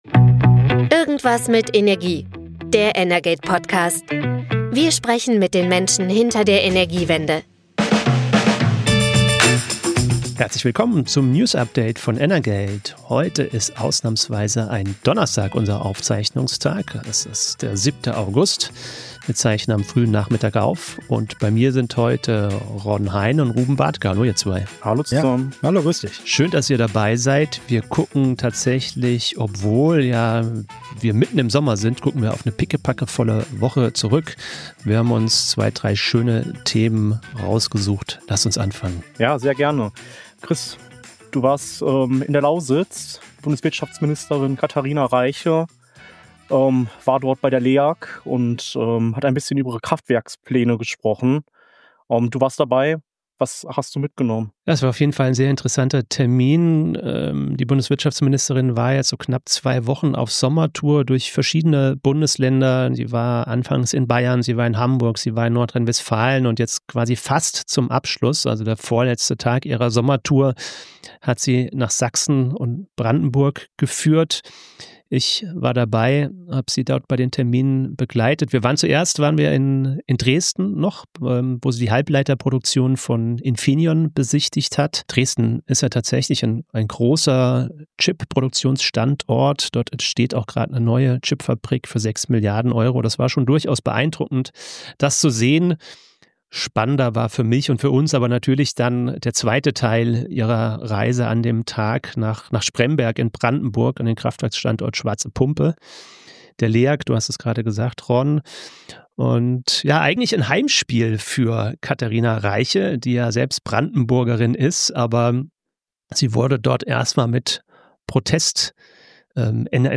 Irgendwas mit Energie – der energate-Podcast wendet sich an alle, die sich für Energiewirtschaft und Energiepolitik interessieren. Jeden Freitag besprechen Expertinnen und Experten aus der energate-Redaktion aktuelle Branchenthemen aus Politik, Unternehmen und Märkten – und liefern so einen unterhaltsamen und energiegeladenen Wochenrückblick.